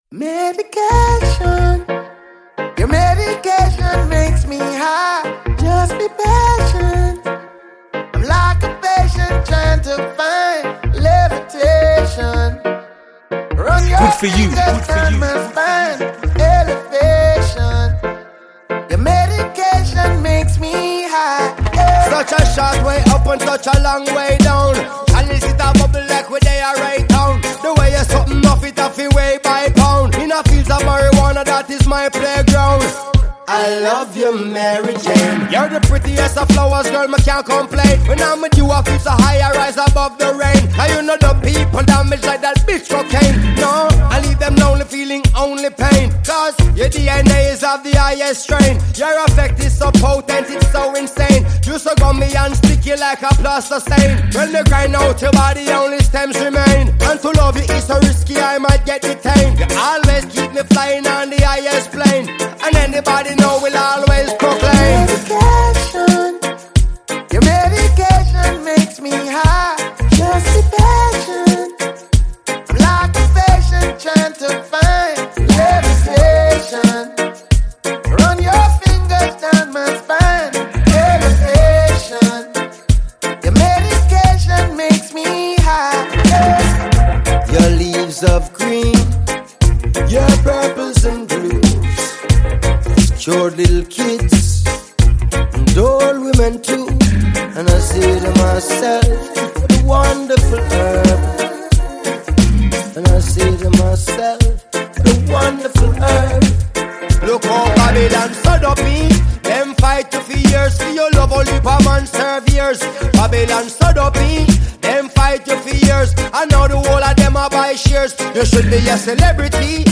vibrant